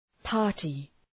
Προφορά
{‘pɑ:rtı}